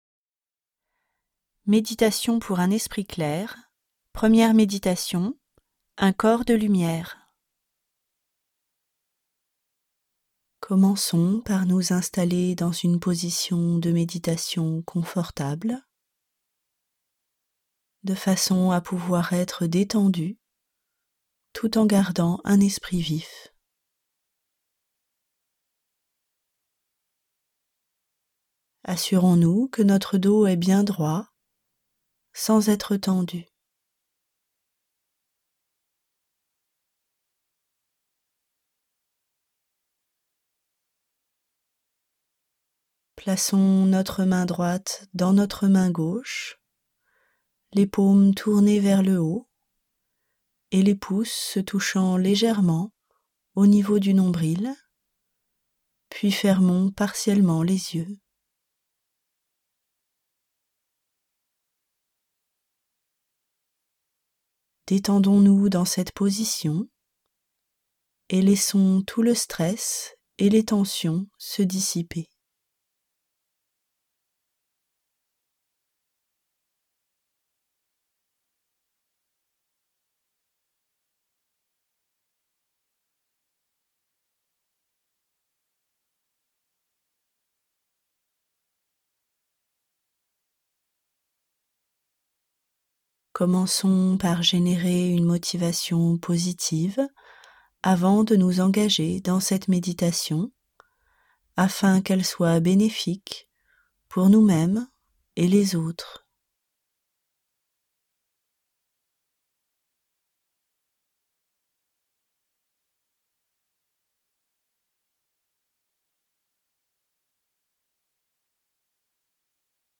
0% Extrait gratuit Méditations pour un esprit clair Un bien-être venant d'une source différente de Guéshé Kelsang Gyatso Éditeur : Editions Tharpa Paru le : 2016 Ces trois méditations guidées sont simples et peuvent être pratiquées par tout le monde. Grâce à une pratique régulière, ces méditations sur l'esprit nous aident à faire l'expérience de la clarté de notre esprit et de la paix intérieure.